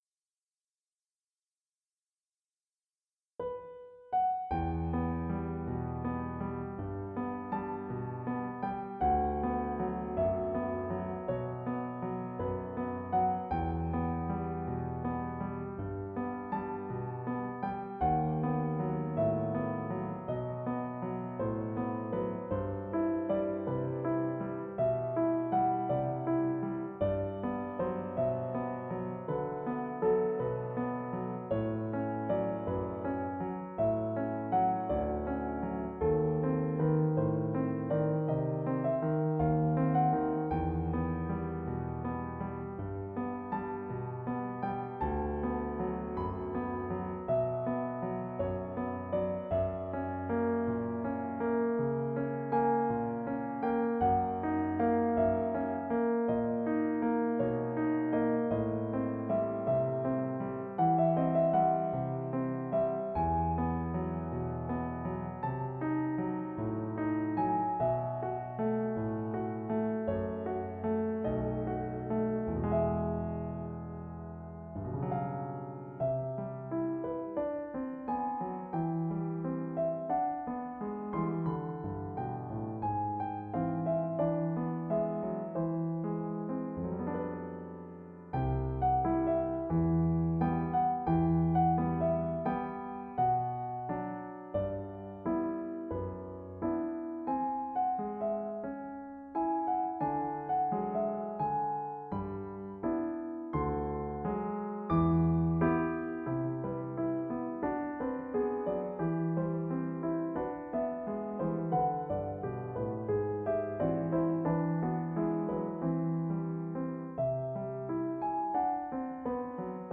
Lucentum Nocturne - Piano Music, Solo Keyboard